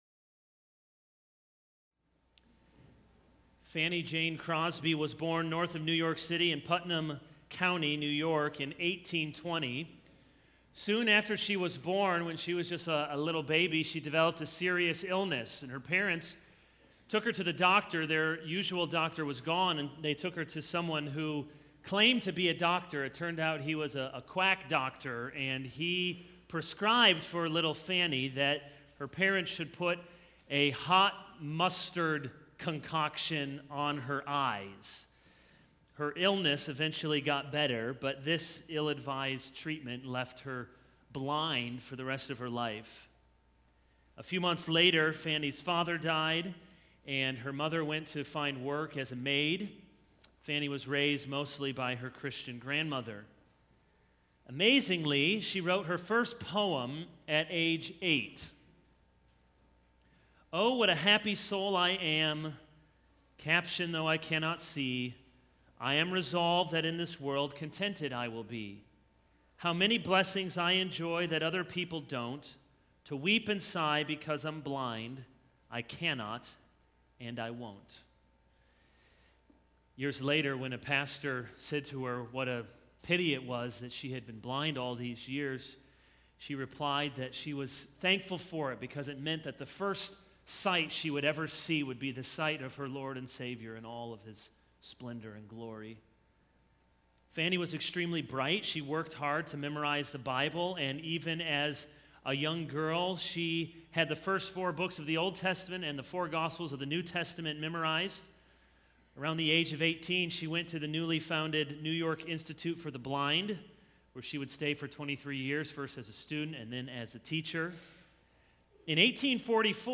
This is a sermon on Exodus 4:10-17.